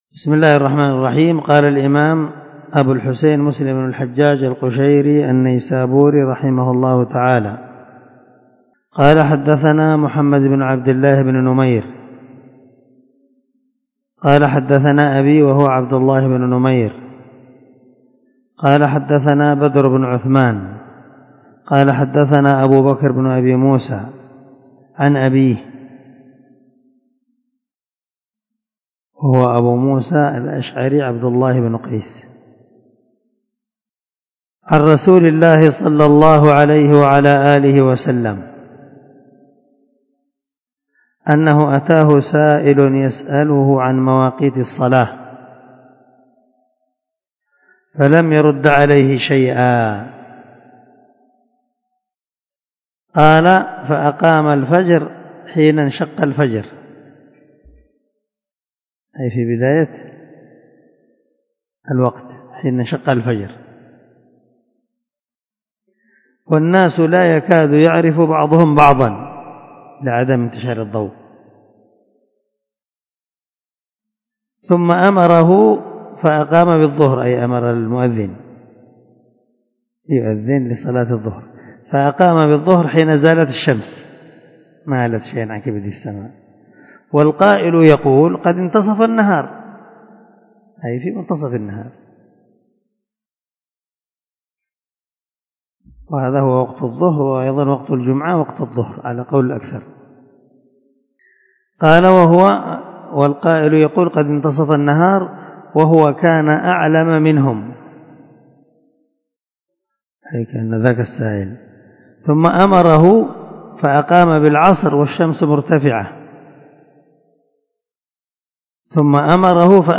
390الدرس 62من شرح كتاب المساجد ومواقيت الصلاة حديث رقم ( 614 ) من صحيح مسلم